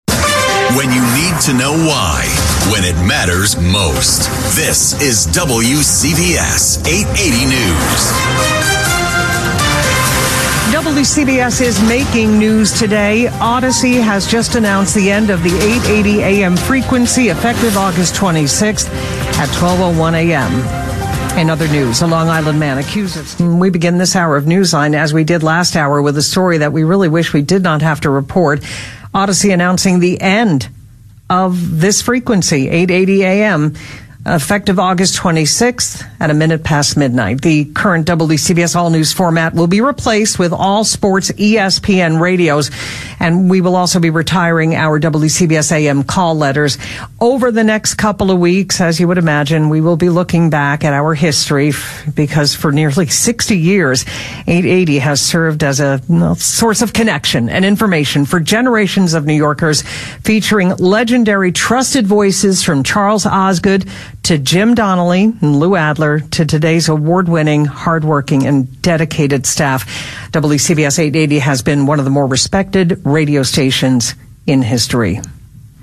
Door de bekendmaking was WCBS opeens nieuws op de eigen zender.
WCBS-880-aankondiging-stoppen.mp3